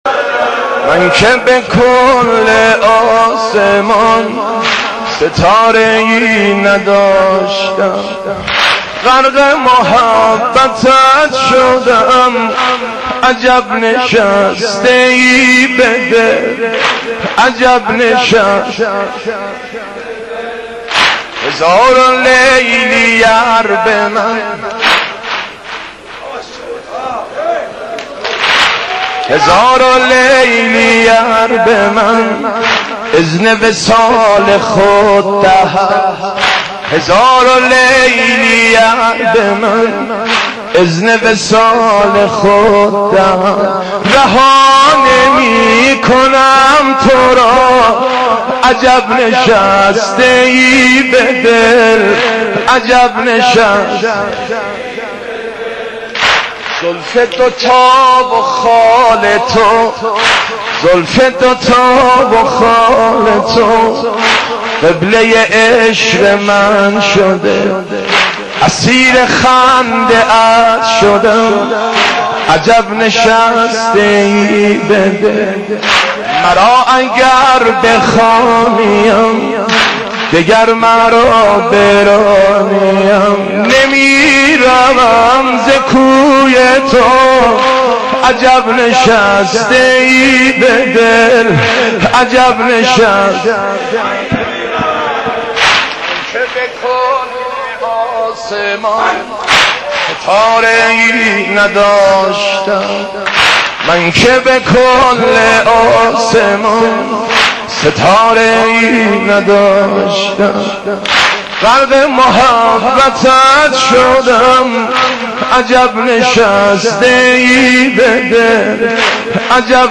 مداحی صوتی